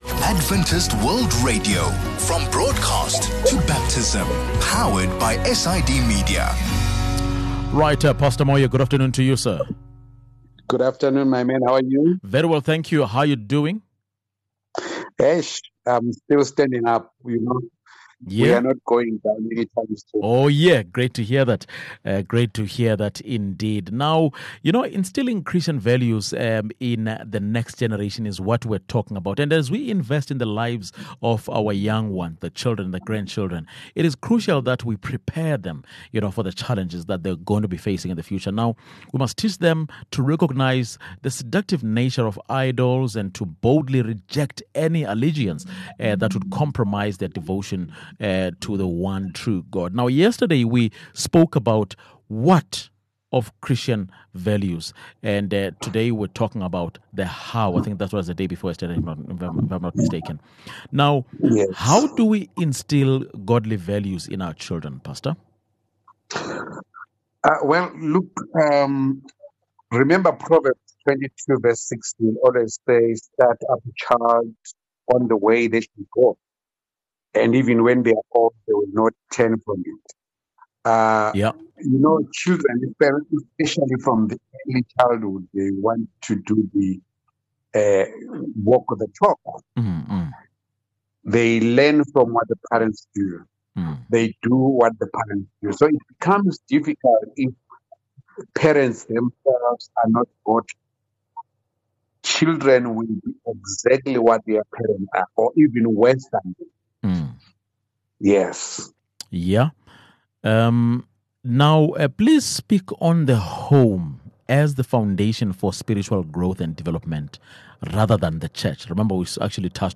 This conversation focuses on the family as the foundation for a child’s spiritual development.